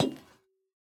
Minecraft Version Minecraft Version 1.21.5 Latest Release | Latest Snapshot 1.21.5 / assets / minecraft / sounds / block / copper_bulb / step5.ogg Compare With Compare With Latest Release | Latest Snapshot
step5.ogg